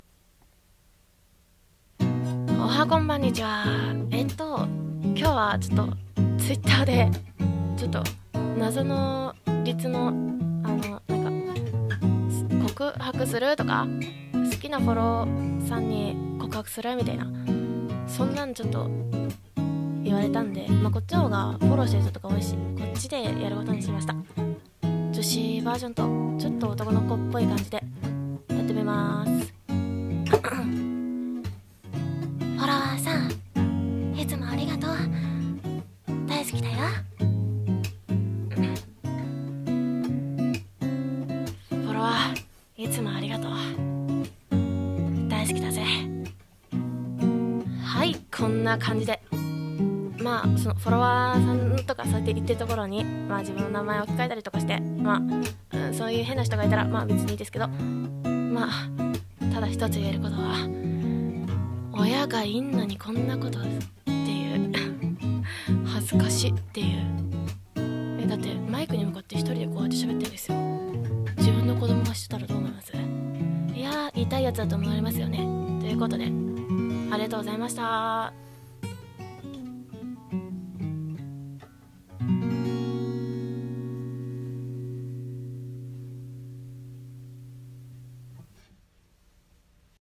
BGM のんびりと 声劇/ラジオ